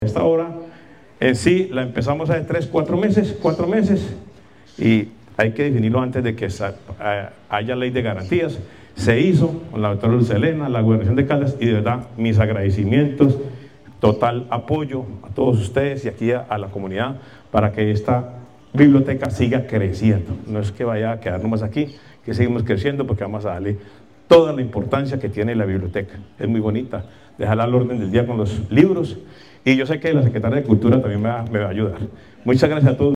Alcalde de Victoria, Juan Alberto Vargas Osorio.
Alcalde-Victoria-Juan-Alberto-Vargas-Osorio-2.mp3